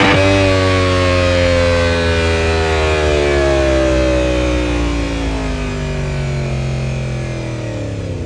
rr3-assets/files/.depot/audio/Vehicles/f1_03/f1_03_decel.wav
f1_03_decel.wav